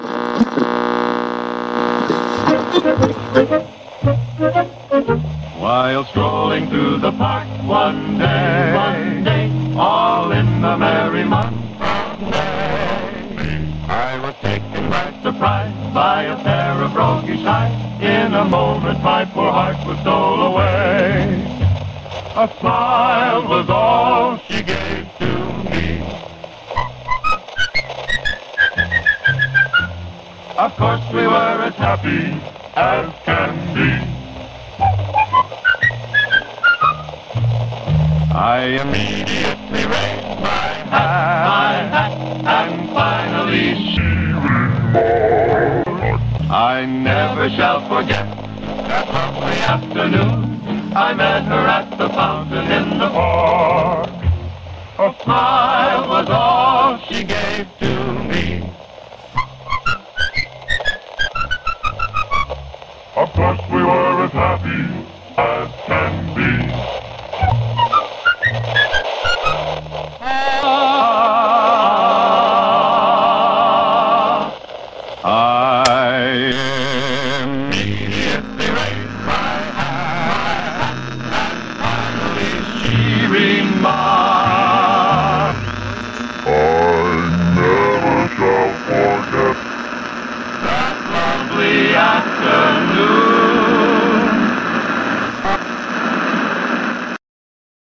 You’re about to abandon the radio when it comes to life with a violent energy! The dials spin madly, jumping from station to station until landing on an unsettling tune.